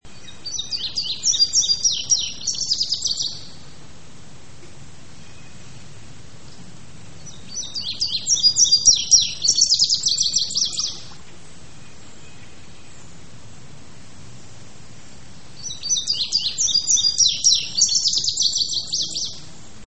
Łuszczyk indygo - Passerina cyanea
głosy